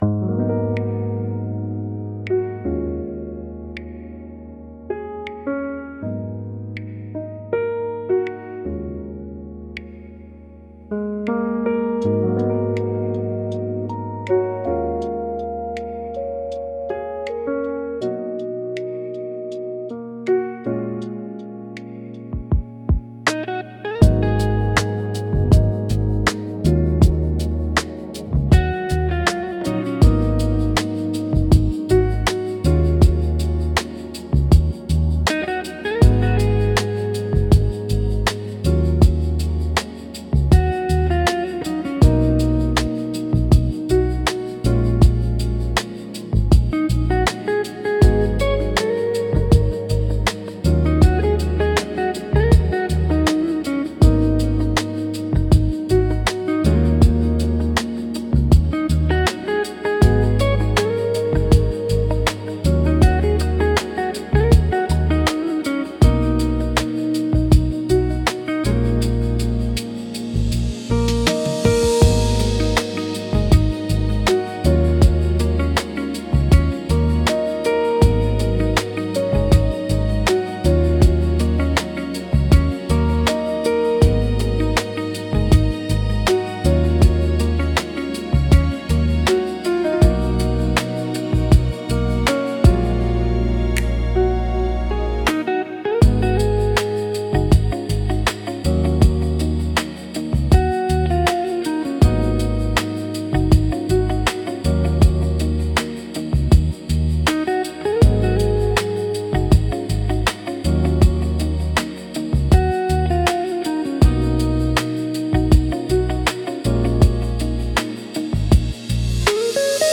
チルアウトは、ゆったりとしたテンポと滑らかで広がりのあるサウンドが特徴のジャンルです。
リラックス効果の高いメロディと穏やかなリズムにより、心地よい安らぎの空間を作り出します。
穏やかで心地よい雰囲気作りに重宝されるジャンルです。